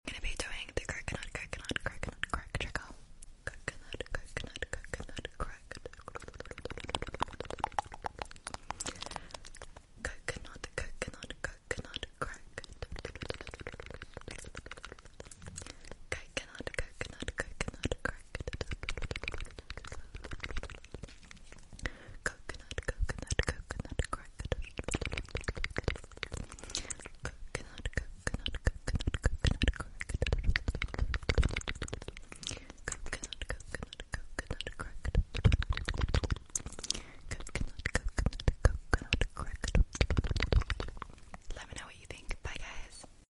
🥥🌧 Coconut Rain Trigger By Sound Effects Free Download